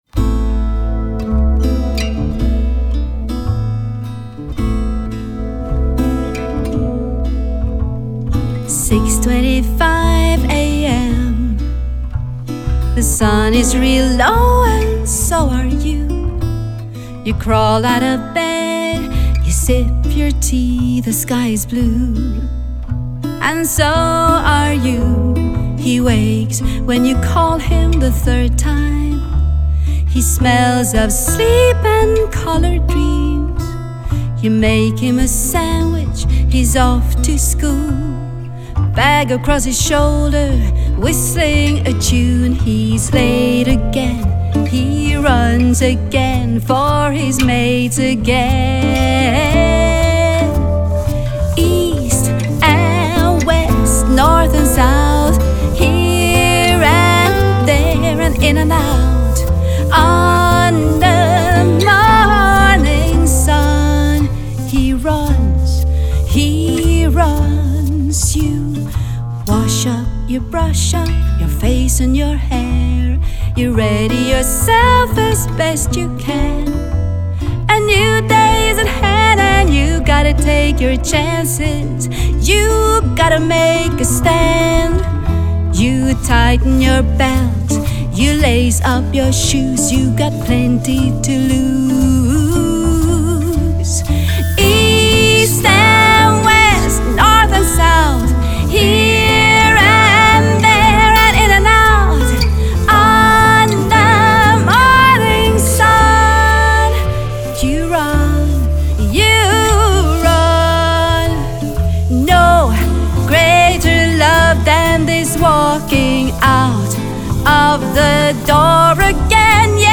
Recorded and mixed in Musikkloftet Studio AS